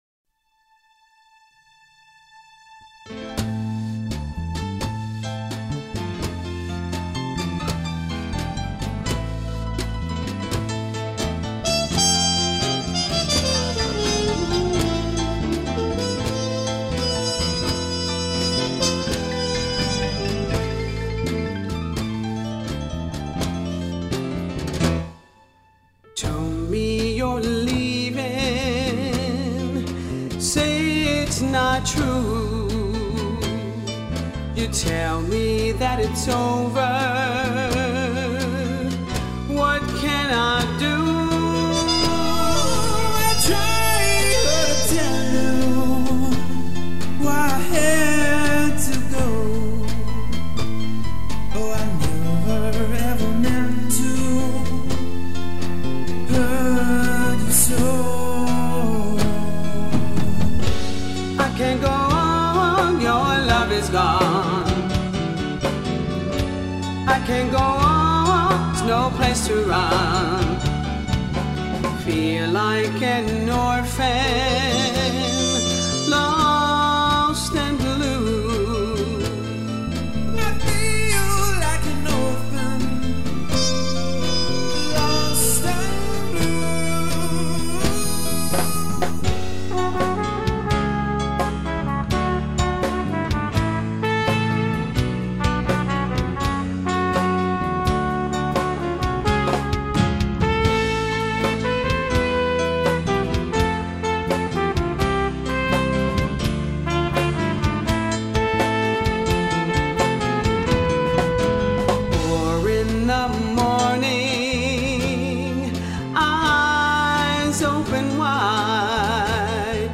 Trumpet
Bass
Drums
Keyboards
Recorded at Pacifica Studios, Cleveland, Ohio